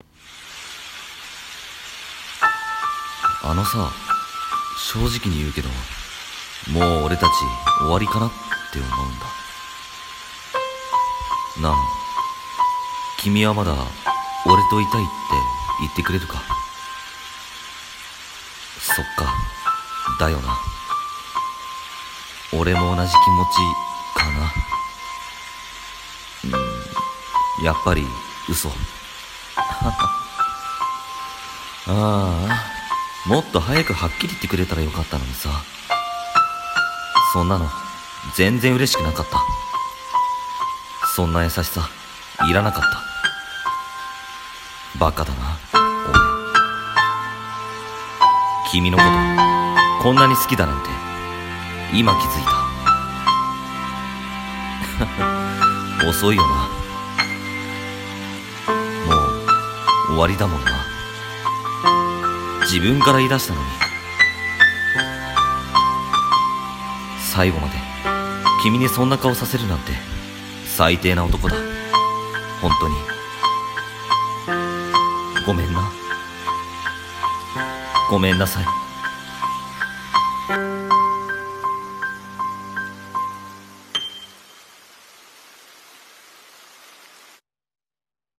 声劇】最低な自分